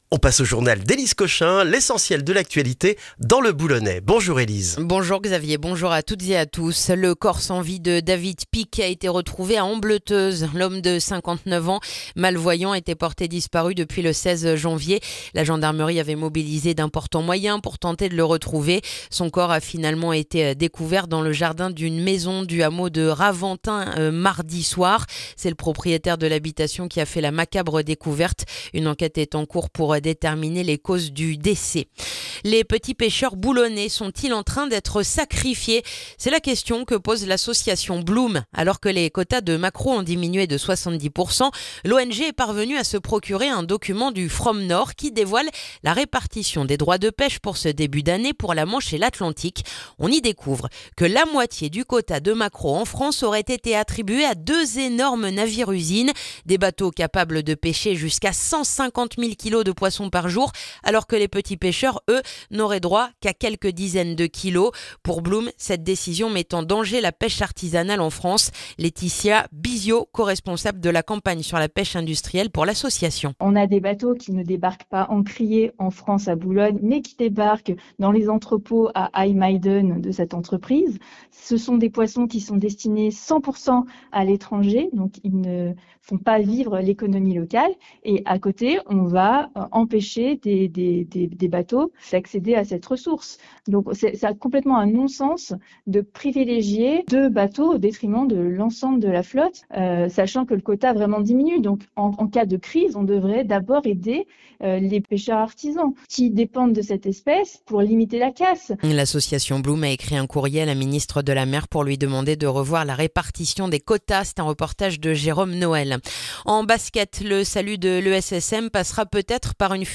Le journal du jeudi 29 janvier dans le boulonnais